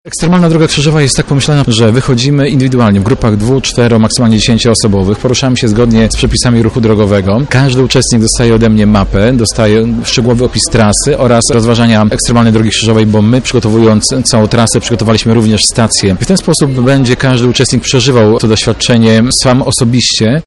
EDK-ksiądz.mp3